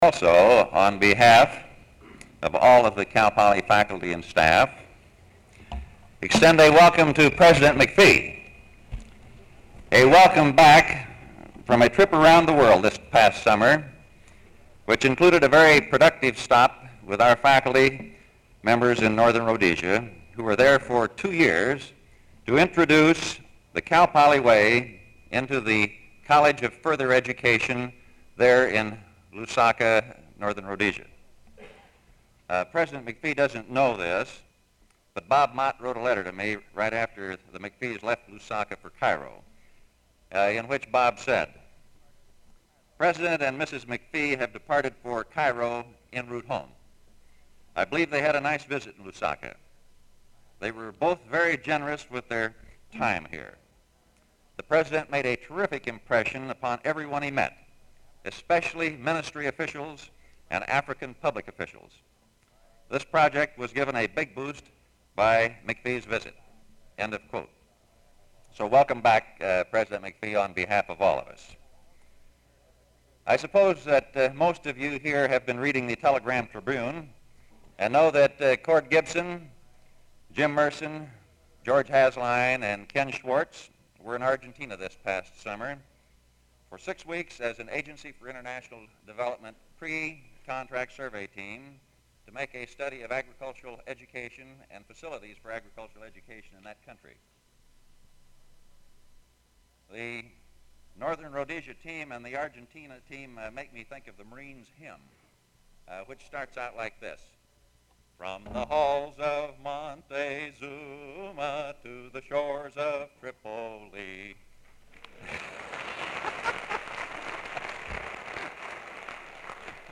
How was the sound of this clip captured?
Form of original Open reel audiotape Campus San Luis Obispo